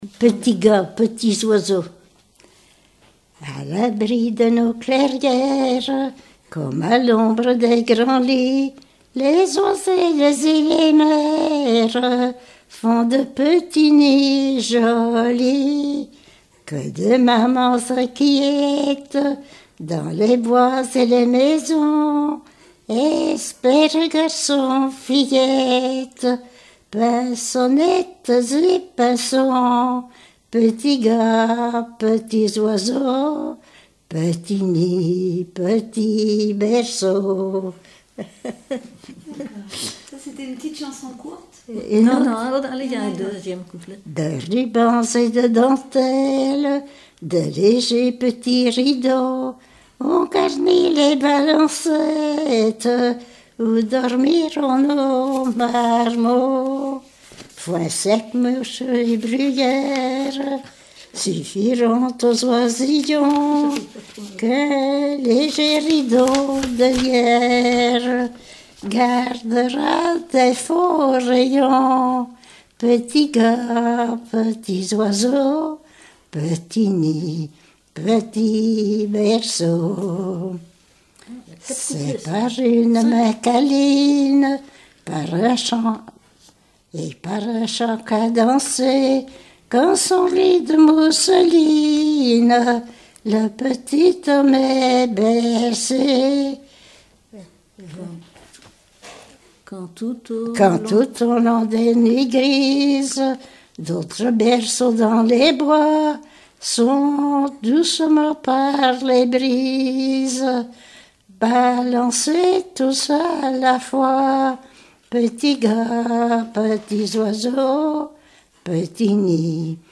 Genre strophique
chansons d'écoles et populaires
Pièce musicale inédite